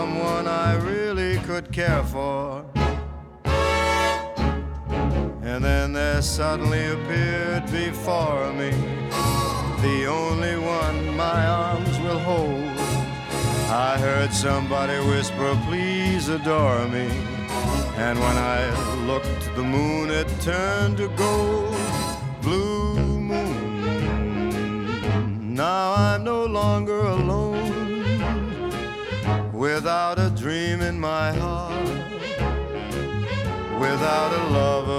Жанр: Поп музыка / Рок / Джаз